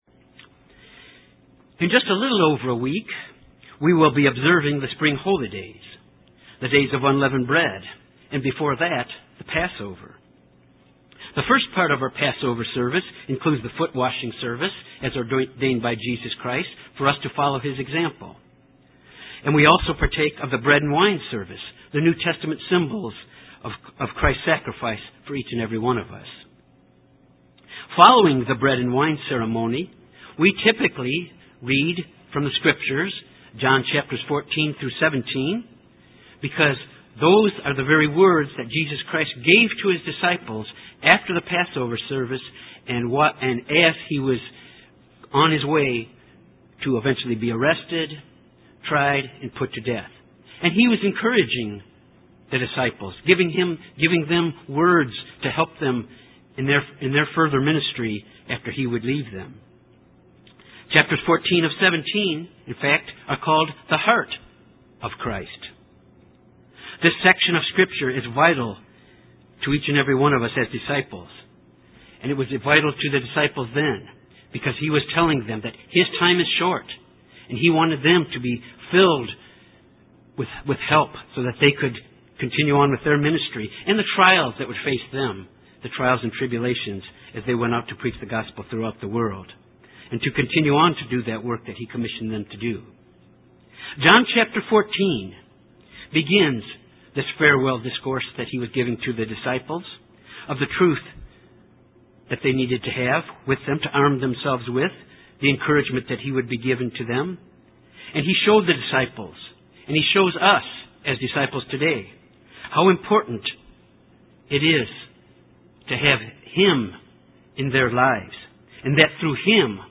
Given in Little Rock, AR Jonesboro, AR
UCG Sermon Studying the bible?